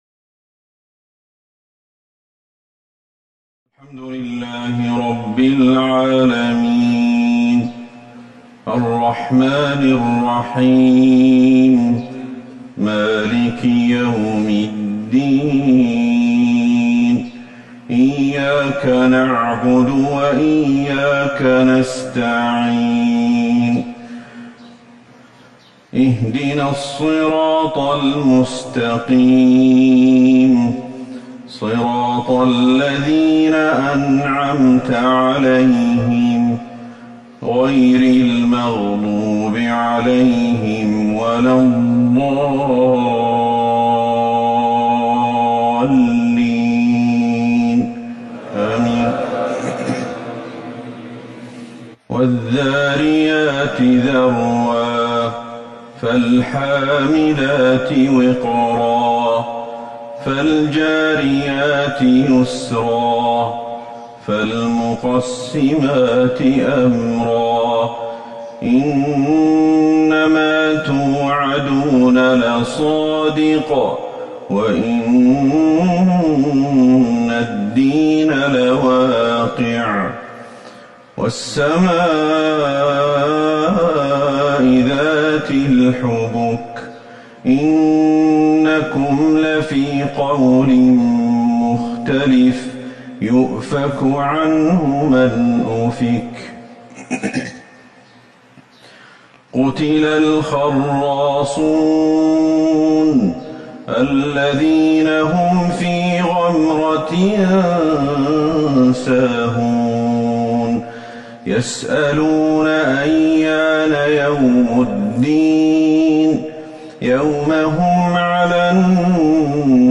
صلاة الفجر ٢٣ جمادى الاخرة ١٤٤١ سورة الذاريات